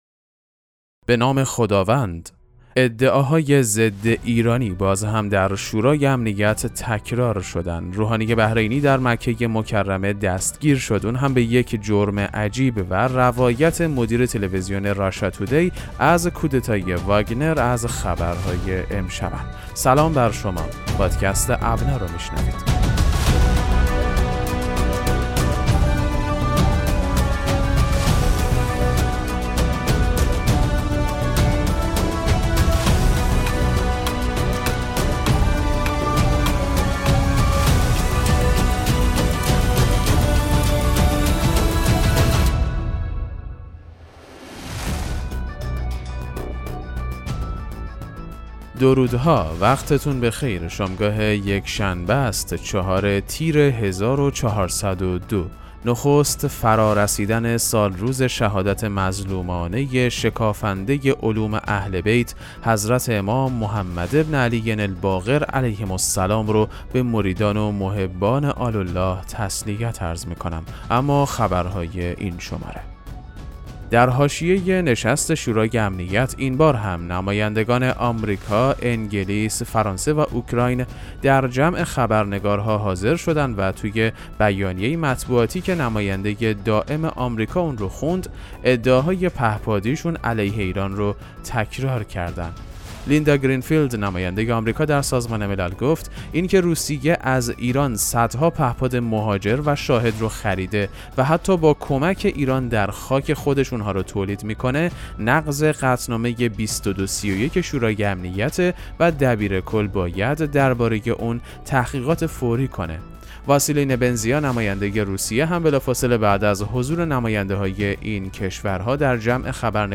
پادکست مهم‌ترین اخبار ابنا فارسی ــ 4 تیر 1402